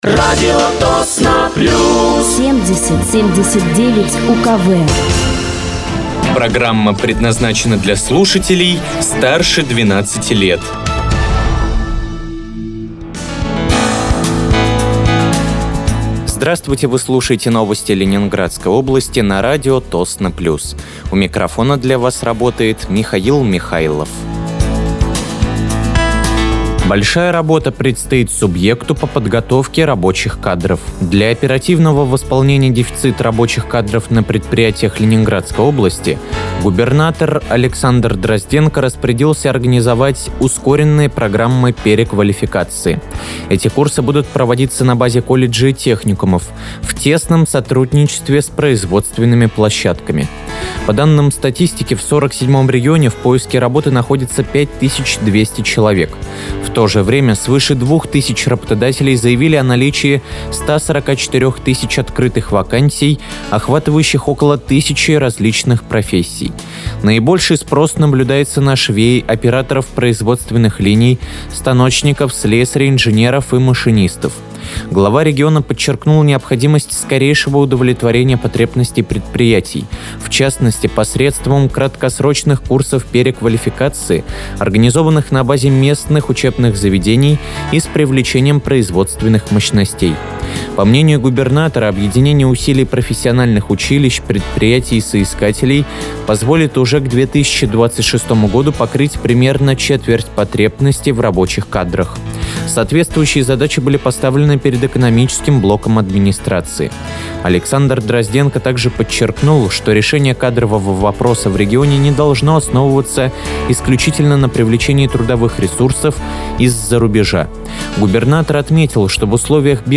Вы слушаете новости Ленинградской области от 24.09.2025 на радиоканале «Радио Тосно плюс».